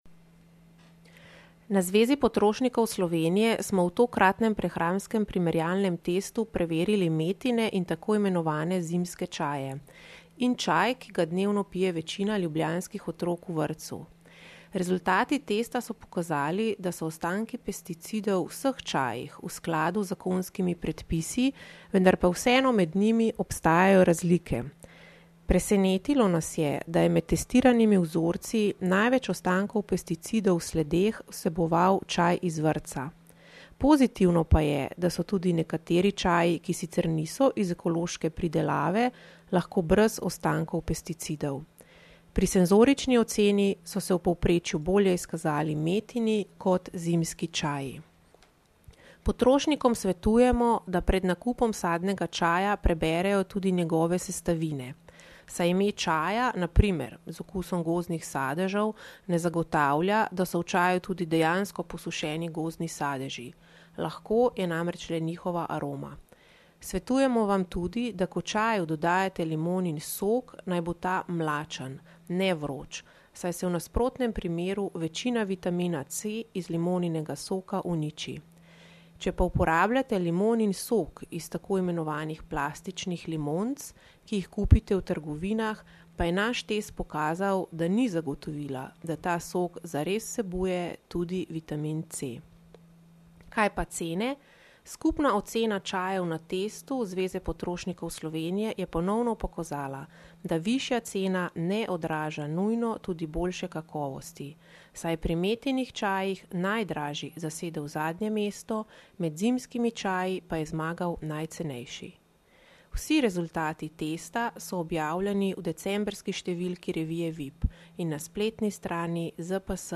Avdio izjava